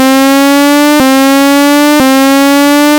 ついでなので、10500〜12000回転でシフトアップする音を作ってみる。
こんな音になった。3回繰り返しているだけだが、シフトアップしているようにも聴こえなくもない。
6kitou_f1_shift3.mp3